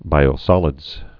(bīō-sŏlĭdz)